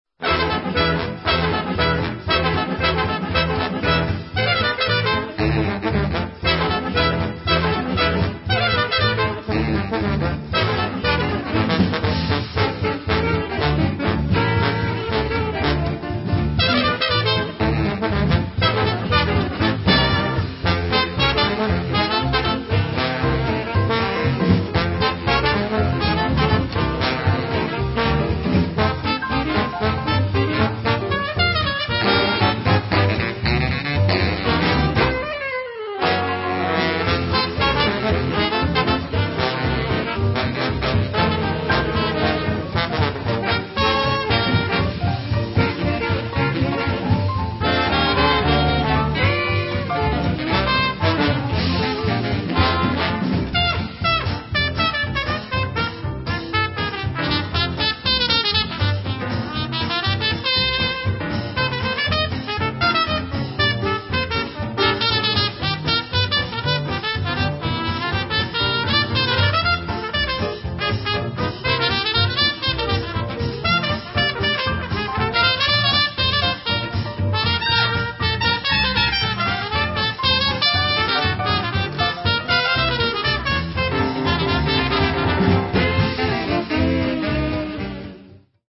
cornetta
trombone, cornetta
sax alto
clarinetto, sax tenore
sax baritono
pianoforte
chitarra
contrabbasso
batteria
voce